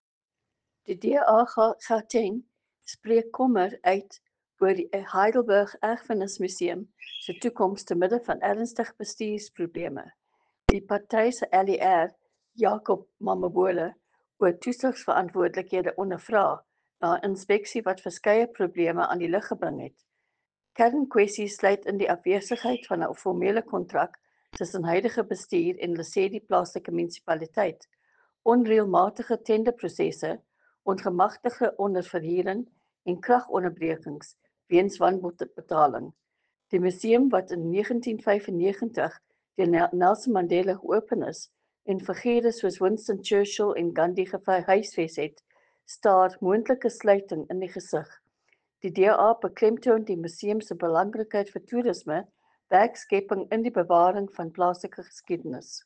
Afrikaans soundbites by Leanne De Jager MPL.